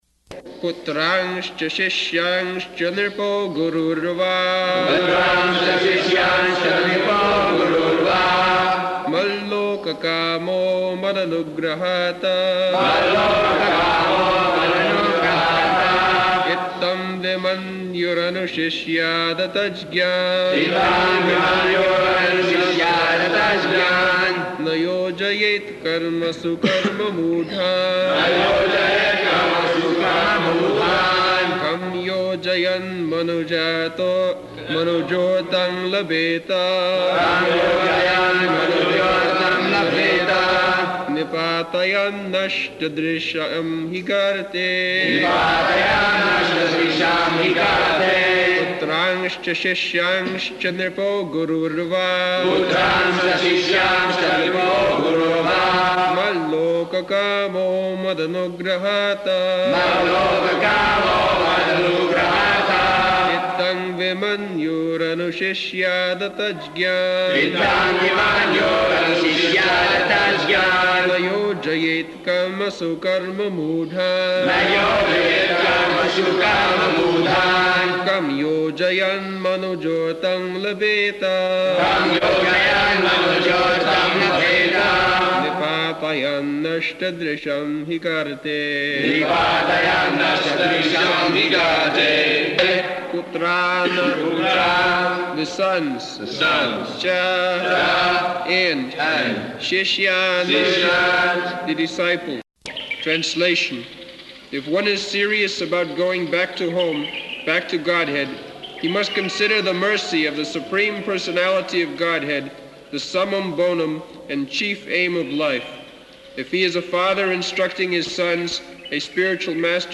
November 3rd 1976 Location: Vṛndāvana Audio file
[leads chanting of verse, etc.]